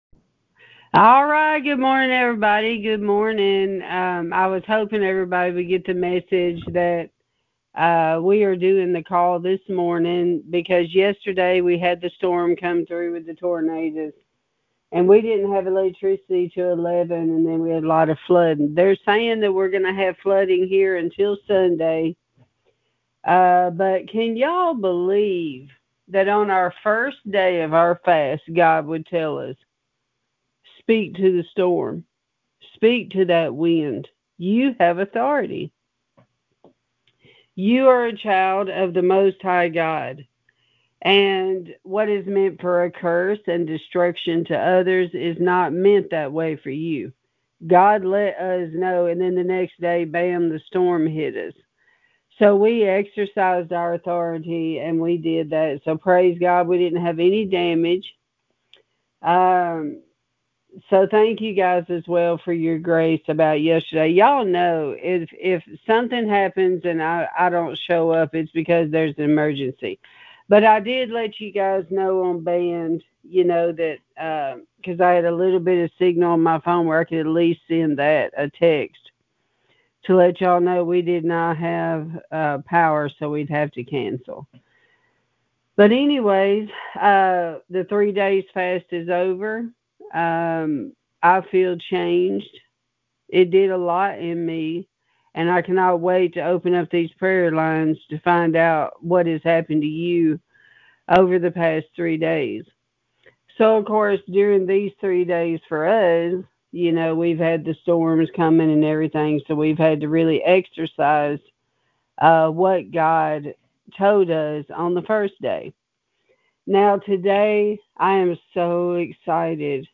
Sermons | Garden of Eden Ministries
This was from Day #3 - end of our three day fast event.